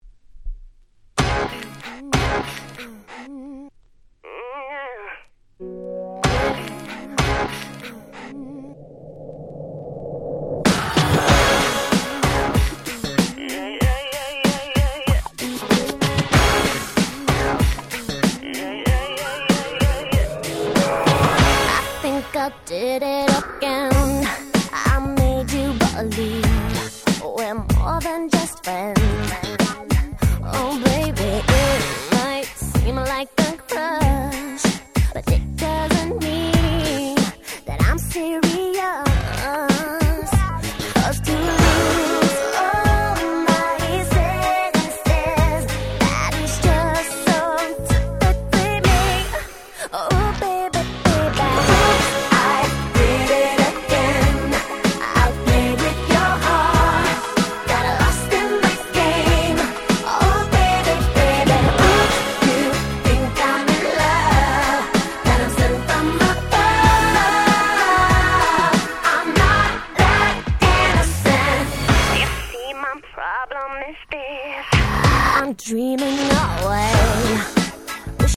00' Super Hit Pops / R&B !!
キャッチー系